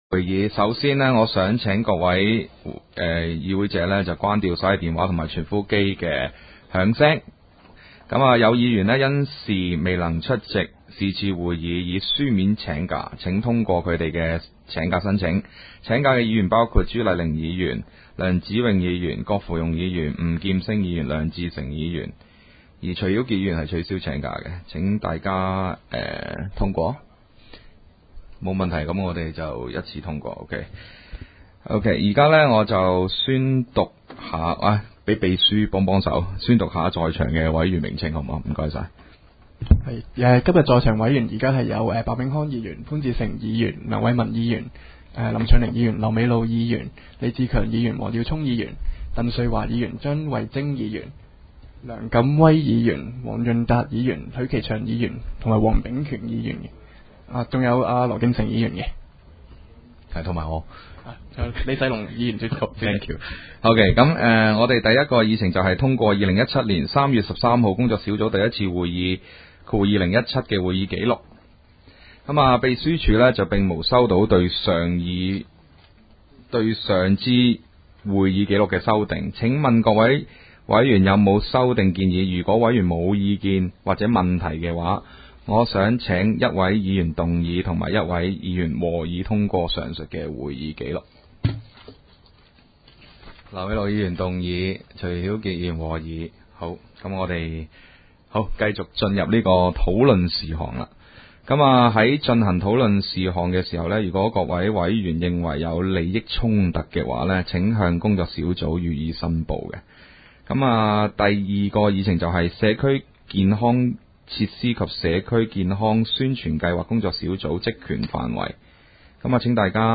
工作小组会议的录音记录